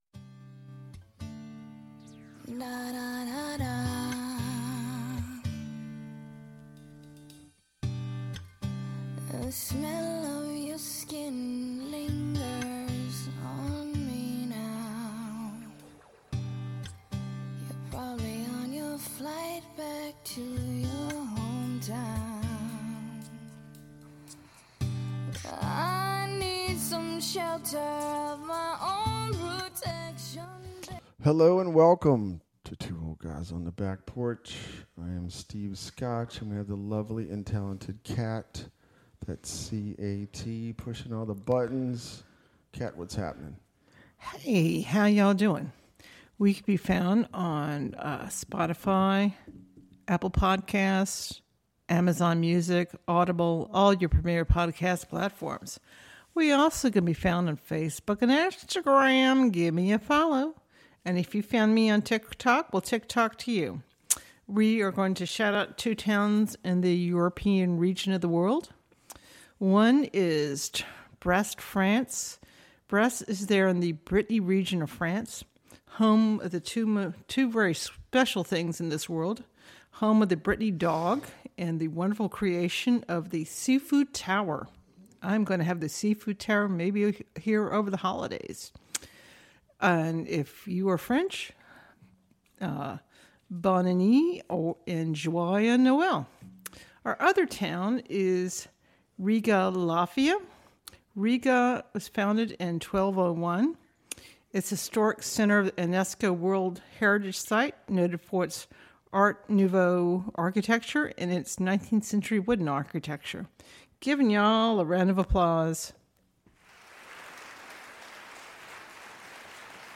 Two Rural Southern Gen Xs discussing Modern Times, Sports, Hunting, Music, and maybe a little Bourbon.
All while on the back porch. Might hear a bird or two. Podcasted from Virginia